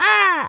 crow_raven_squawk_01.wav